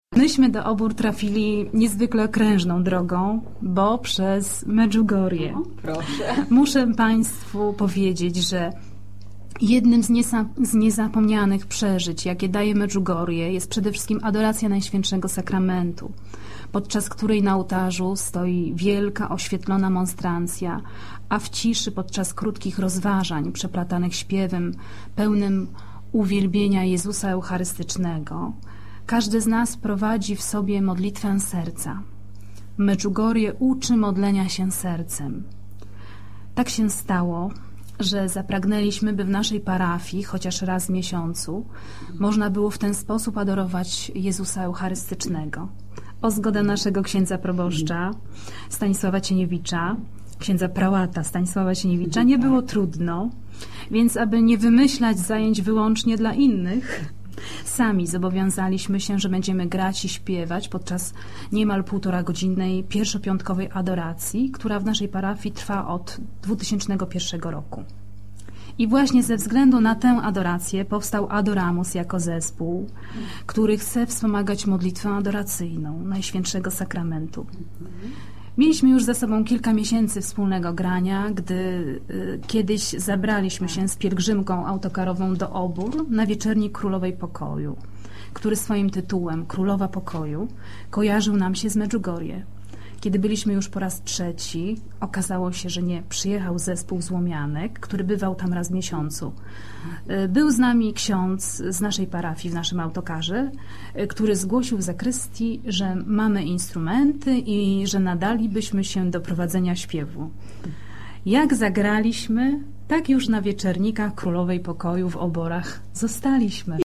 Wywiad radiowy – Radio Głos 18.08.2004 cz. 1